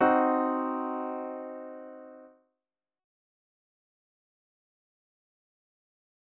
C diminished chord
C-diminished-chord.mp3